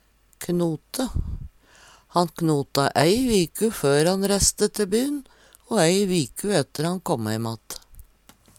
knote - Numedalsmål (en-US)